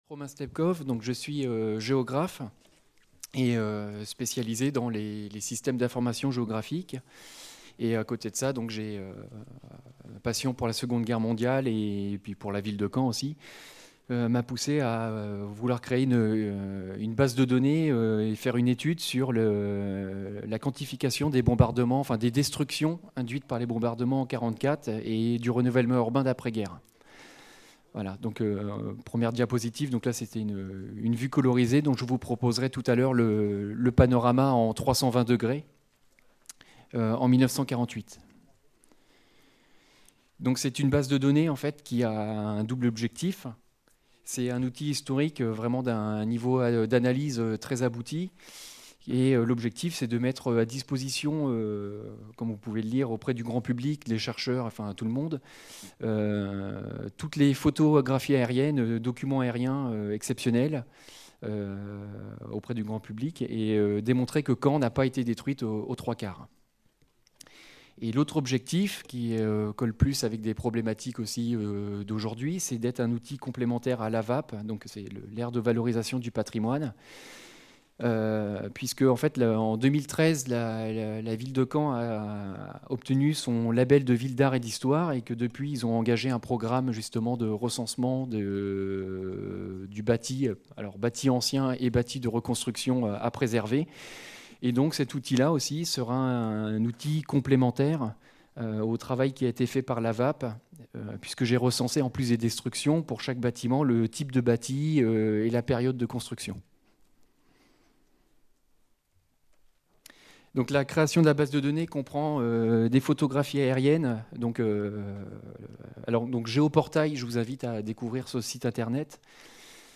Cette communication a été filmée lors du colloque international intitulé De Verdun à Caen - L'archéologie des conflits contemporains : méthodes, apports, enjeux